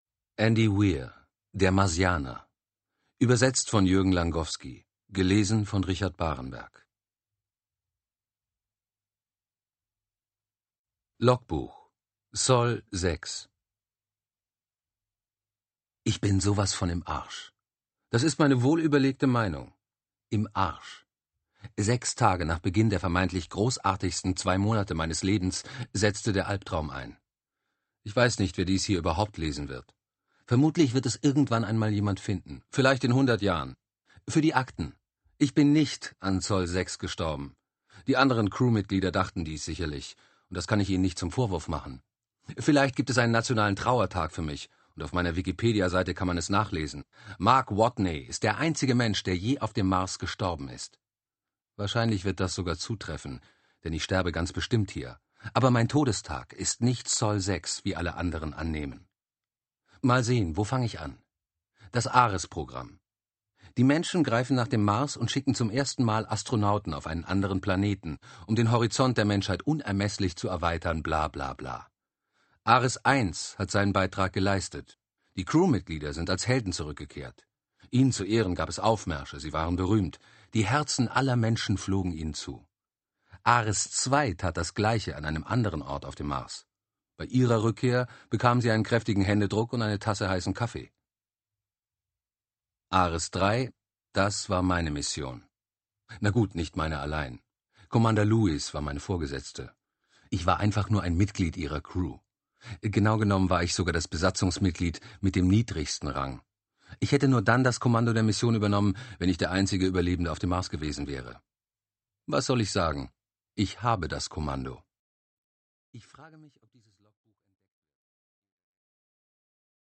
digital digital digital stereo audio file Notes